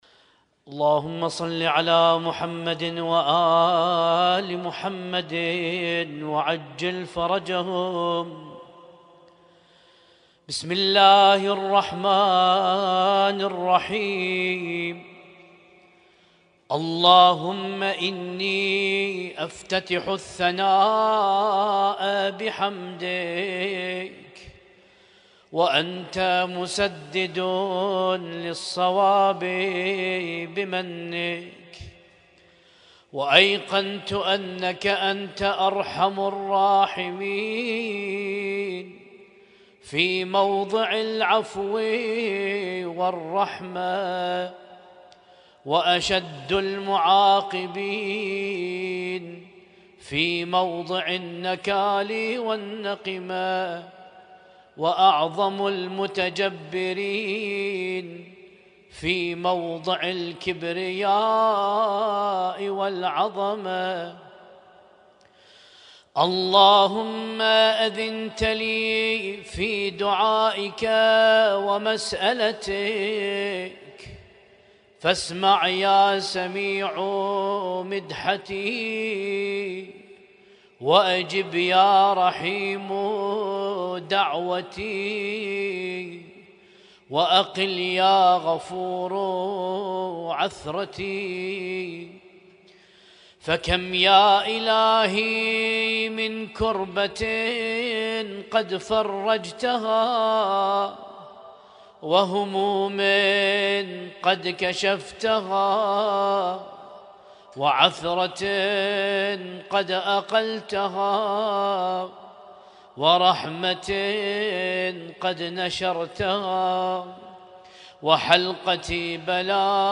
ليلة 27 من شهر رمضان المبارك 1447هـ